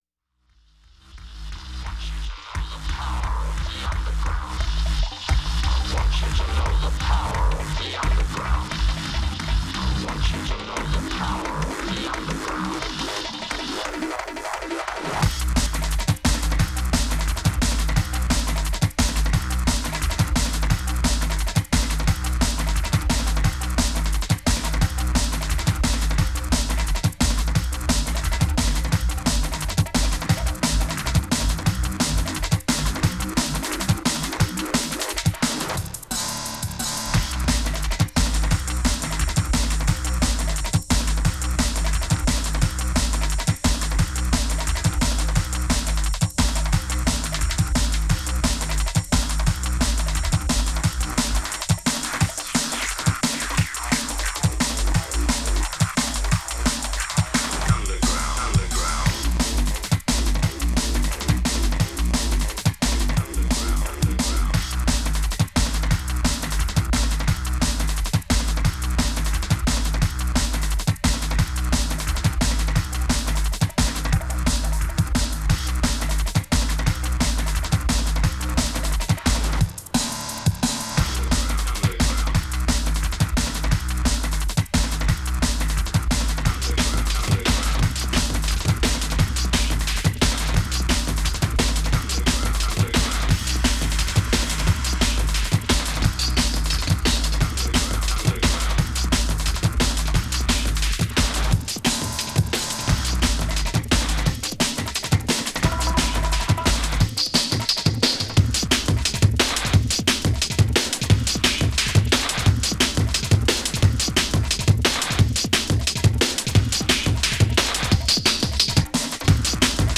DJ mix
Dance Disco/House Electronic